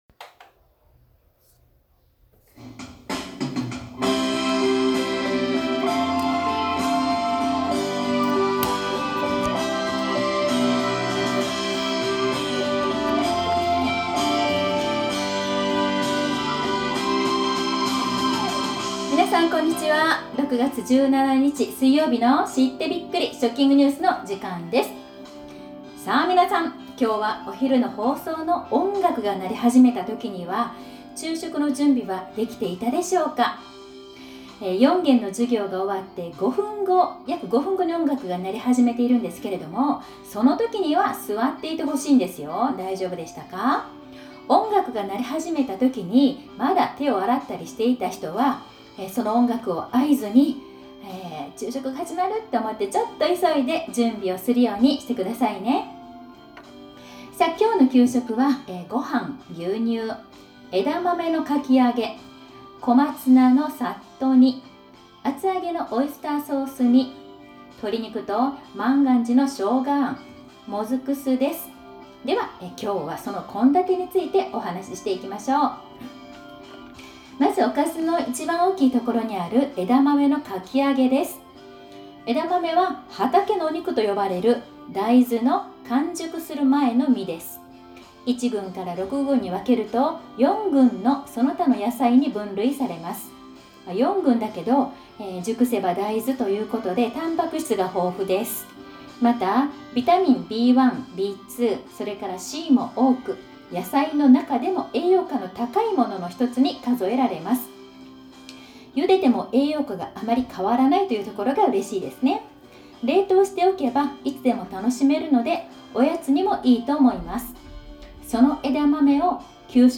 今日の食育放送は，給食の献立についてお話しました。１つ１つのメニューがどのように作られているのかお話しています。
（今日はちゃんと録音できました。）